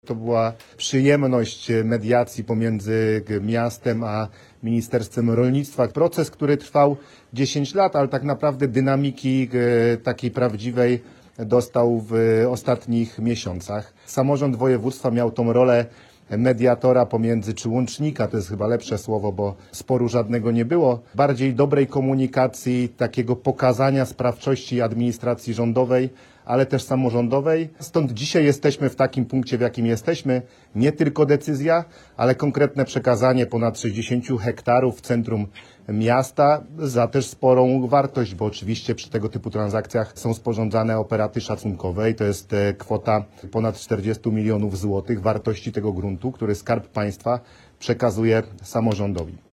O sukcesie działań mówi Paweł Gancarz, Marszałek Województwa Dolnośląskiego.